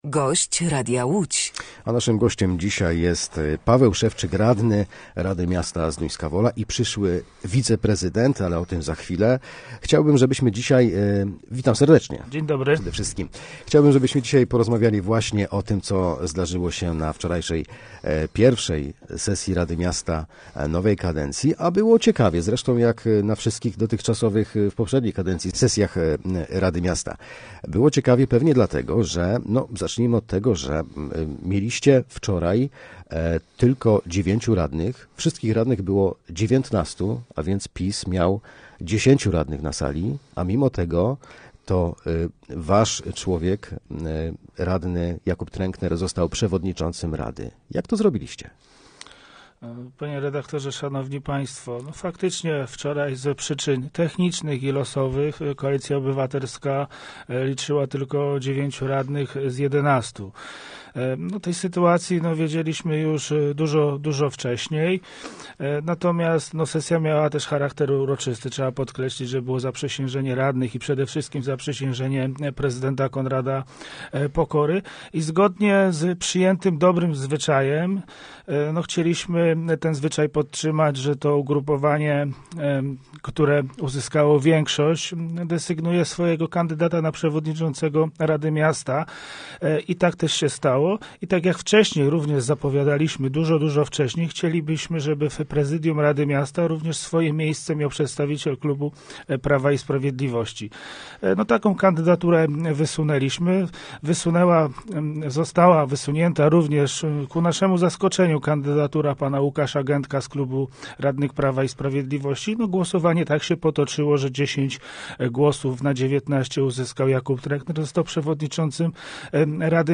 Gościem Radia Łódź Nad Wartą był radny Rady Miasta Zduńska Wola, Paweł Szewczyk, z Koalicji Obywatelskiej.